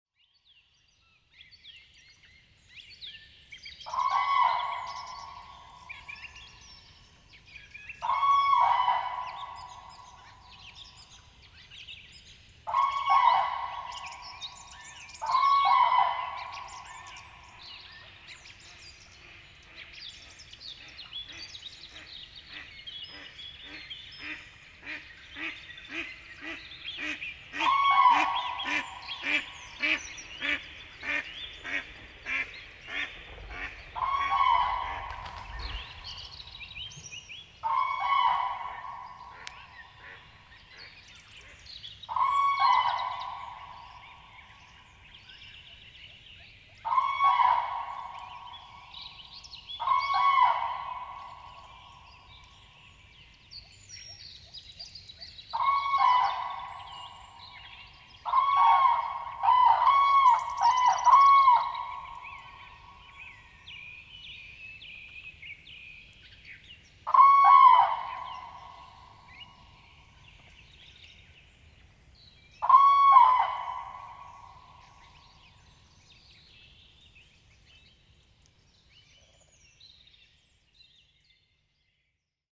constant traffic noise (mainly airplanes).
PFR09931-1, 1-00, 140406, Common Crane, territorial call,
Mallard, female, calls in flight, Grumsin, Germany